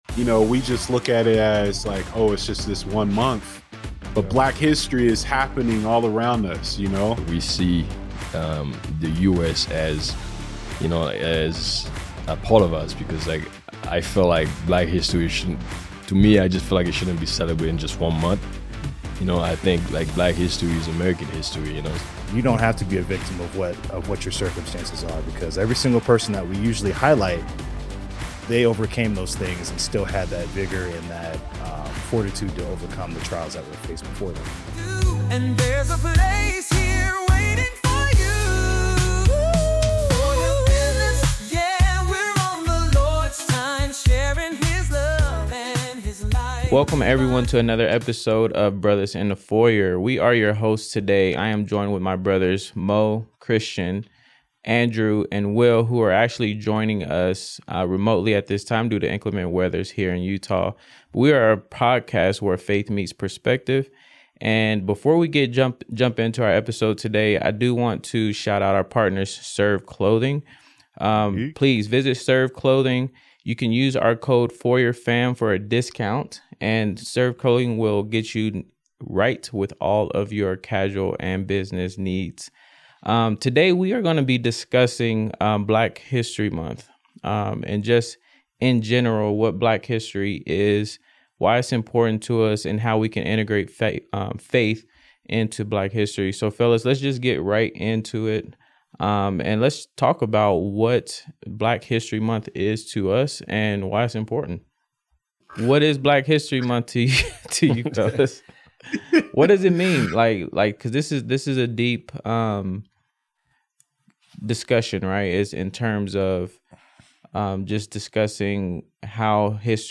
In this episode of Brothers in the Foyer, the hosts dive into the significance of Black History Month, discussing why Black history is American history and should be acknowledged year-round.